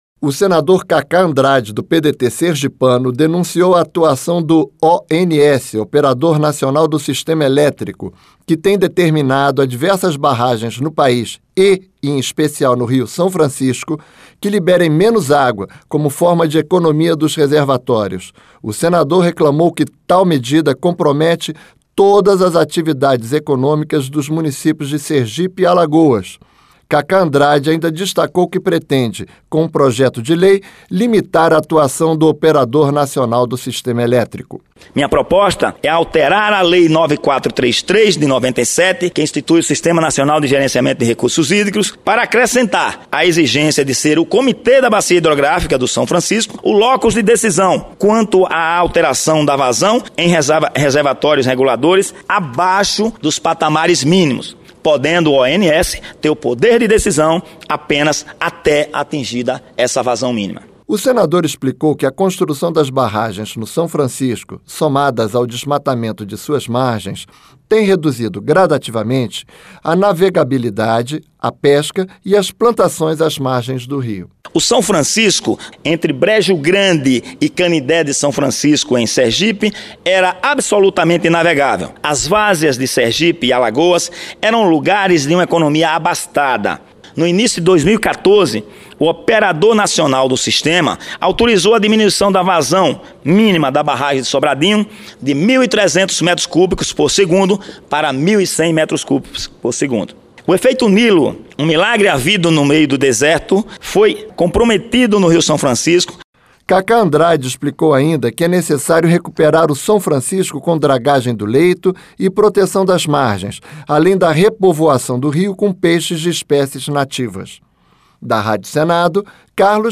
Senador Kaká Andrade